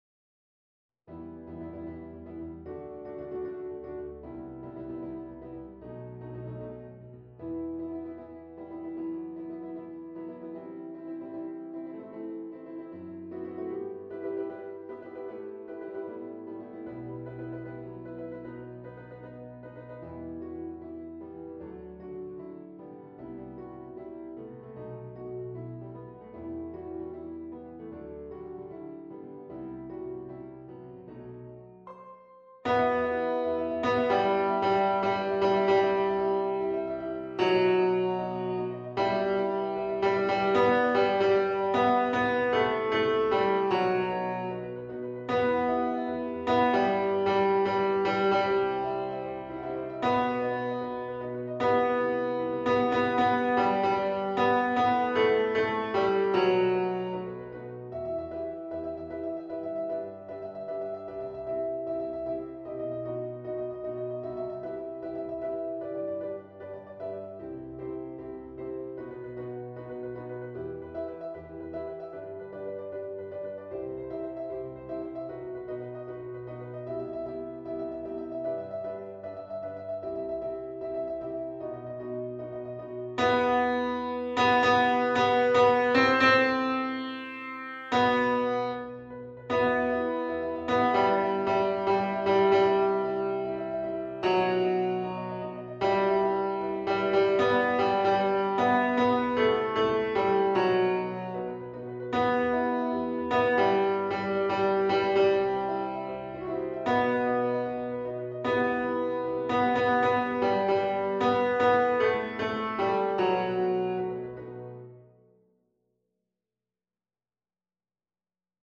Piano / Vocal Only